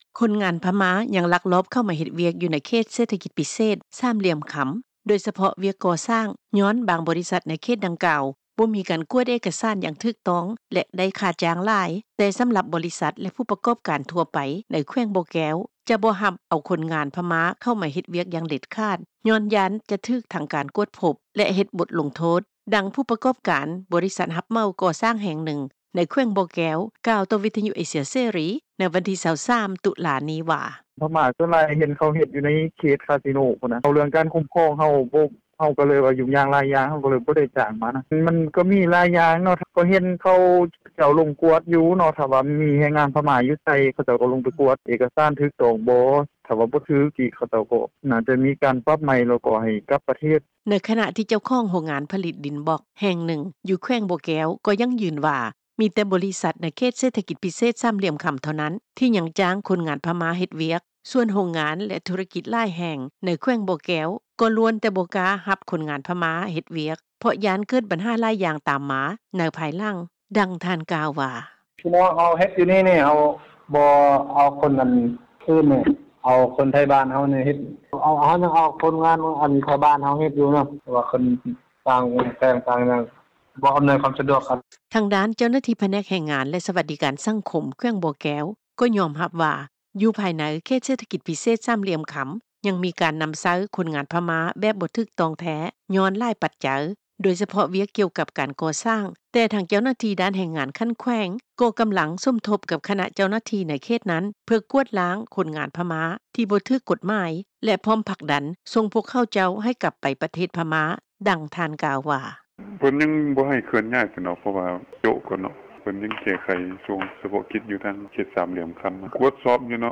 ທາງດ້ານເຈົ້າໜ້າທີ່ ພະແນກແຮງງານ ແລະ ສະຫວັດດີການສັງຄົມ ແຂວງບໍ່ແກ້ວ ກໍຍອມຮັບວ່າ ຢູ່ພາຍໃນເຂດເສດຖະກິດພິເສດ ສາມຫຼ່ຽມຄຳ ຍັງມີການນຳໃຊ້ຄົນງານພະມ້າແບບບໍ່ຖືກຕ້ອງແທ້ ຍ້ອນຫຼາຍປັດໃຈ, ໂດຍສະເພາະ ວຽກກ່ຽວກັບການກໍ່ສ້າງ, ແຕ່ທາງເຈົ້າໜ້າທີ່ດ້ານແຮງງານຂັ້ນແຂວງ ກໍກຳລັງສົມທົບ ກັບ ຄະນະເຈົ້າໜ້າທີ່ໃນເຂດນັ້ນ ເພື່ອກວາດລ້າງຄົນງານພະມ້າ ທີ່ບໍ່ຖືກກົດໝາຍ ແລະ ພ້ອມຜັກດັນ ສົ່ງພວກເຂົາເຈົ້າໃຫ້ກັບໄປປະເທດພະມ້າ, ດັ່ງທ່ານກ່າວວ່າ: